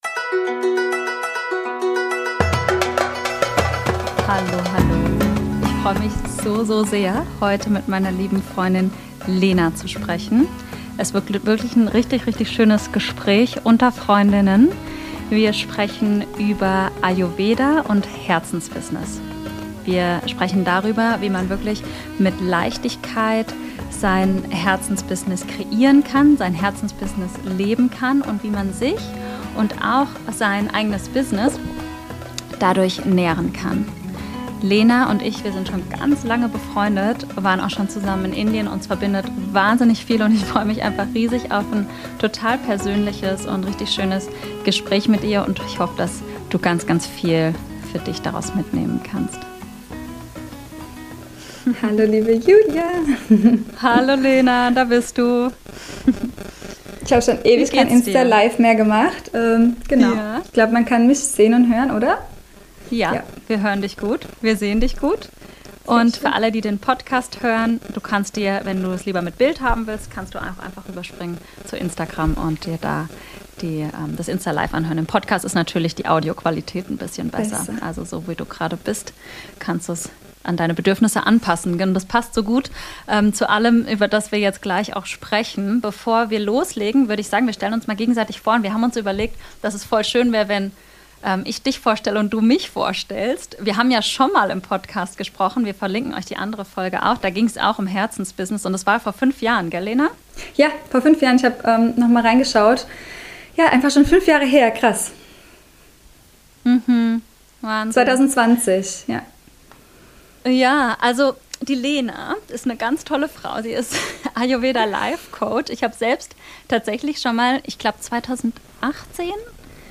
Ein entspanntes Gespräch unter Freundinnen über Ayurveda, Selbstständigkeit & einen gesunden Lebensstil